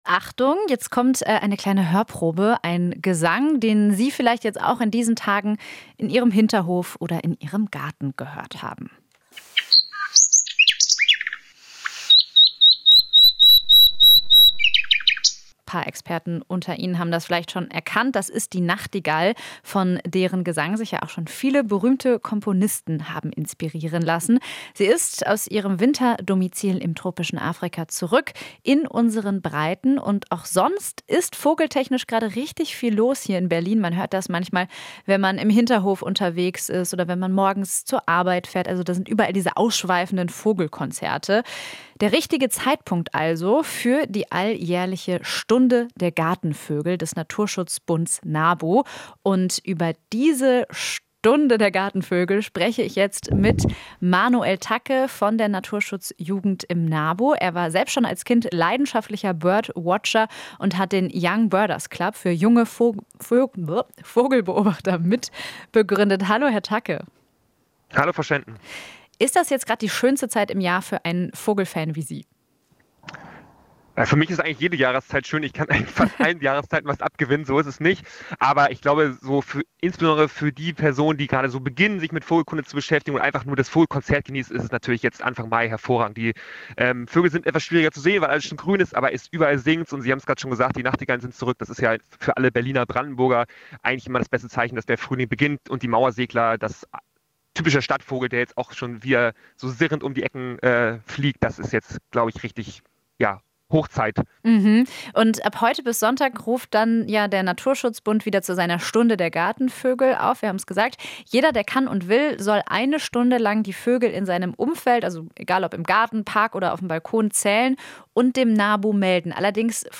Interview - Nabu ruft wieder zur "Stunde der Gartenvögel" auf